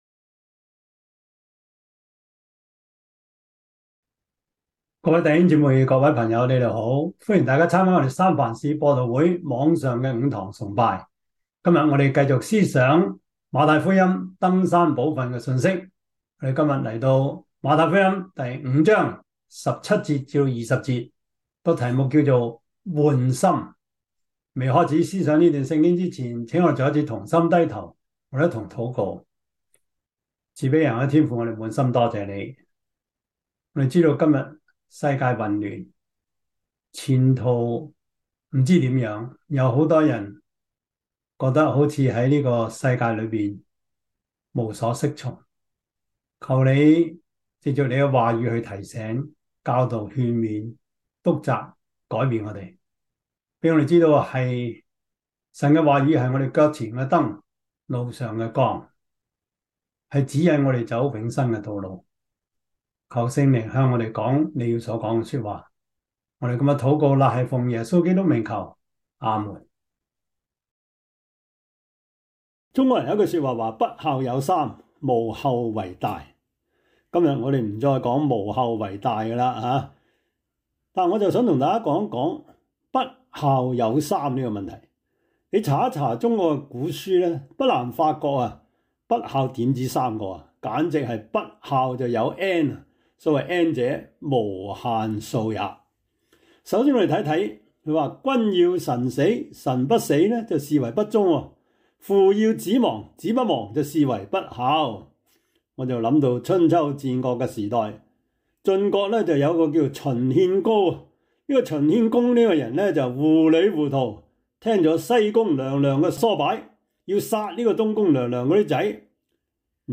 馬太福音 5:17-20 Service Type: 主日崇拜 馬太福音 5:17-20 Chinese Union Version